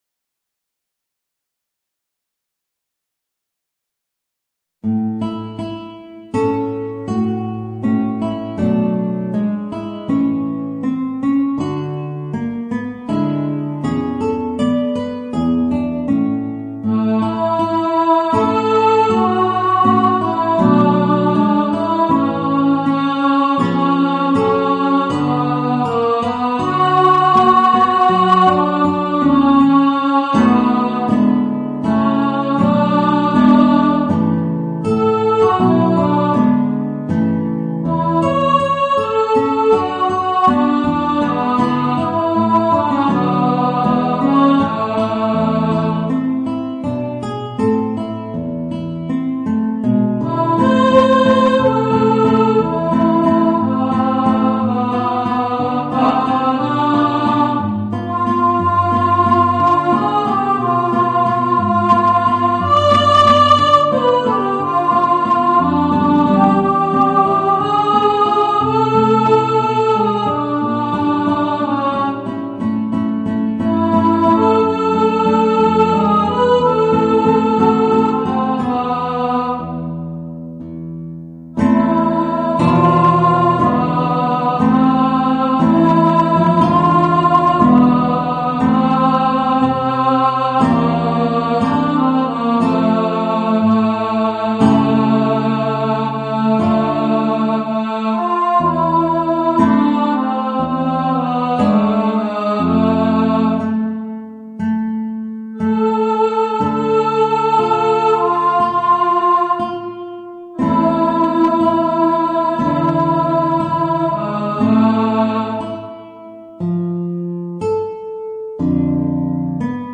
Voicing: Guitar and Alto